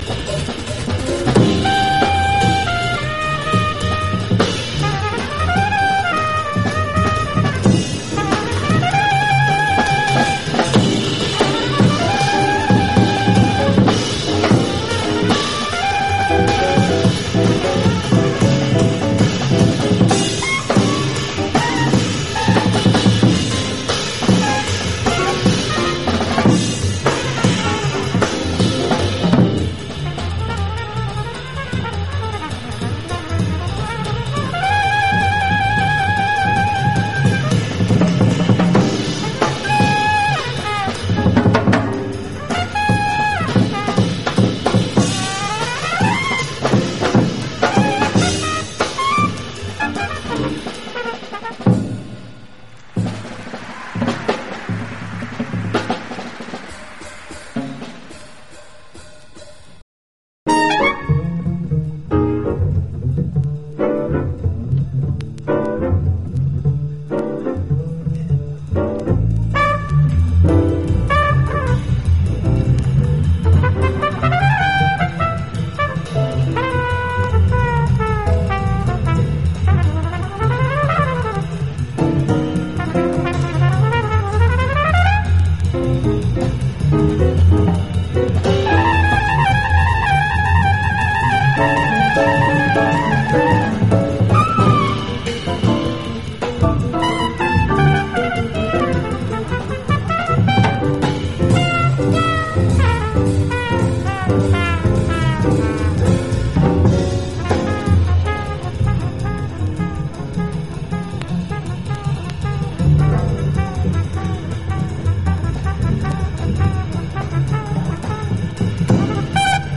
JAZZ / MAIN STREAM / ACOUSTIC SWING
軽妙でコミカル、そしてセンチメンタルでロマンチックなスウィングにウットリと聴き惚れるばかり。